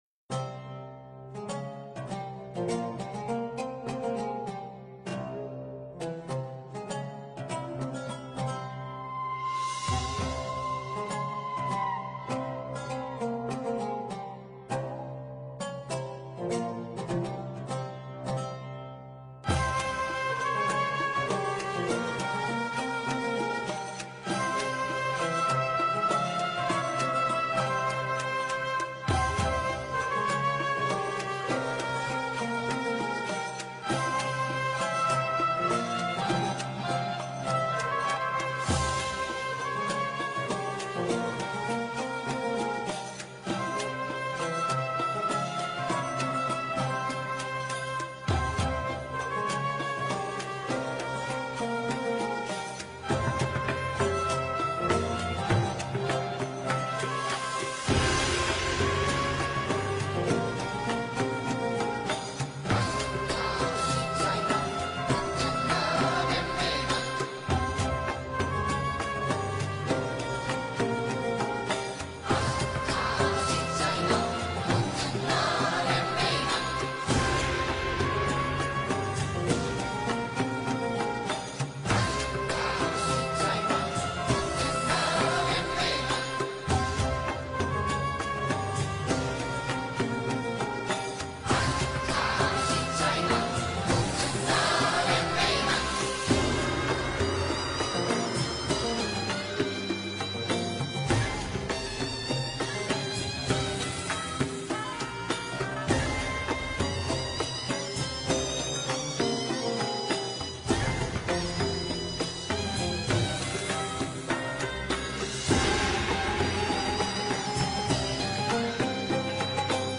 Might fit for terrain based theme if we will go this way some time — for desert specifically.